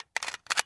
Gun Clip.wav